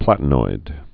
(plătn-oid)